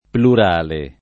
[ plur # le ]